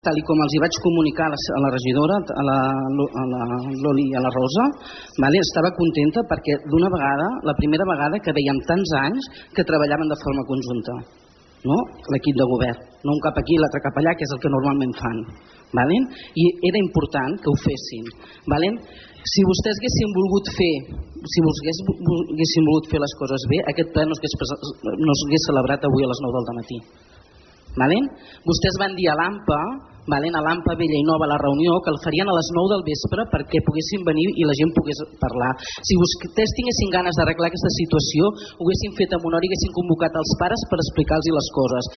Aquest matí s’ha celebrat al consistori la sessió extraordinària del ple municipal per aprovar la modificació de les taxes de l’EMMiD.
Susanna Pla és regidora d’ERC.
susanna-erc-ple-emmid-2.mp3